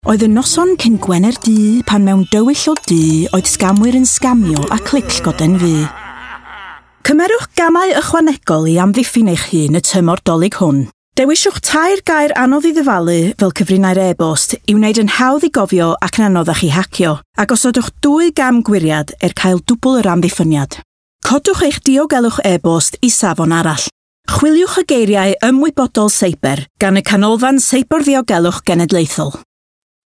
Welsh
Female
Bright
Friendly
Soothing
Warm
NATIONAL CYBER SECURITY COMMERCIAL